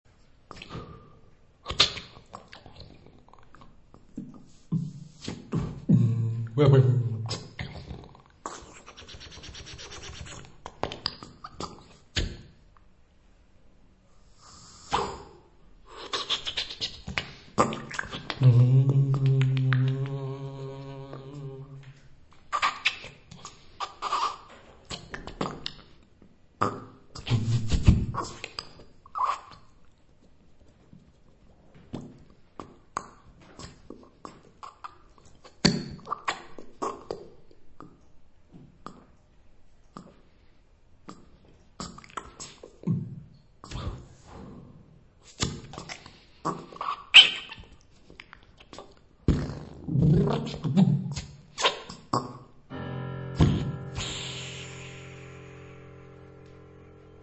Mouth